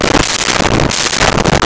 Laundry Coin Op
Laundry Coin Op.wav